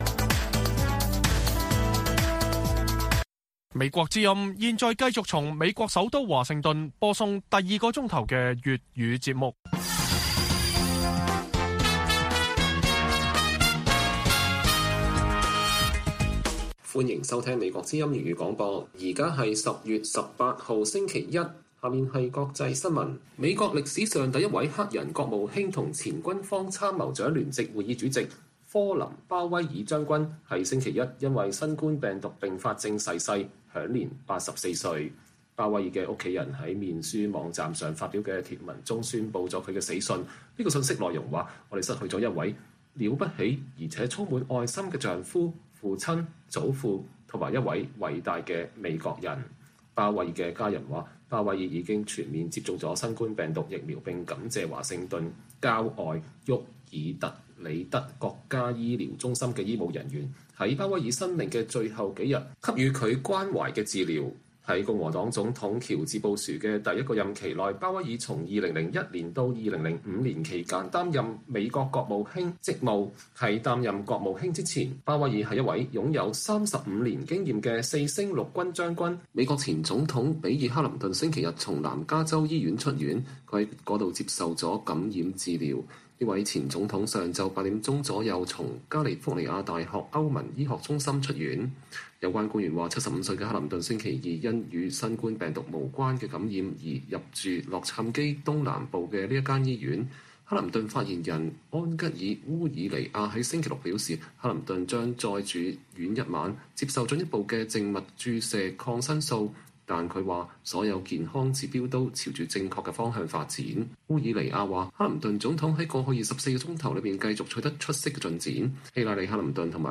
粵語新聞 晚上10-11點: 前國務卿鮑威爾因新冠病毒併發症逝世